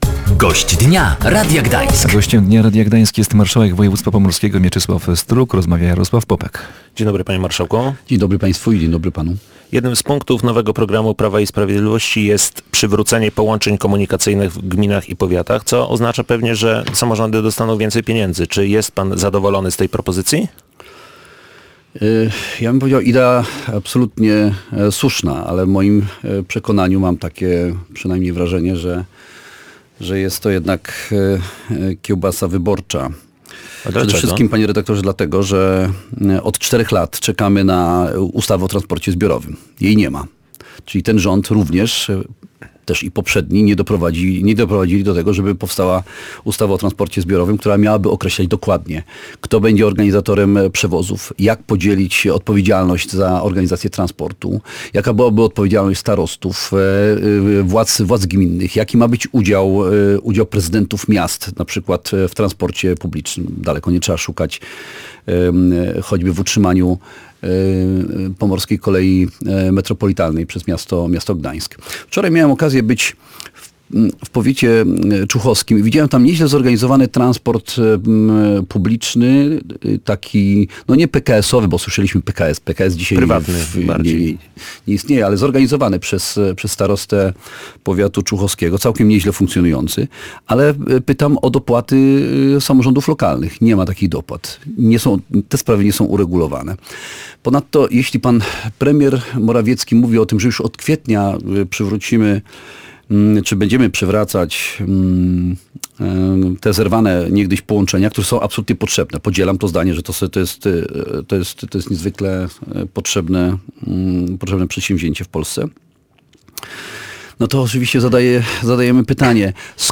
– Konsekwentnie trzymamy się naszego stanowiska od 2007 roku. Mamy wiele wątpliwości, ale one nadal nie zostały rozwiane. Pokazaliśmy w jednoznaczny sposób jaki jest nasz stosunek do tej inwestycji, jakimi dysponujemy argumentami i dzisiaj rządzący biorą za to odpowiedzialność – mówił o przekopie Mierzei Wiślanej marszałek województwa pomorskiego Mieczysław Struk.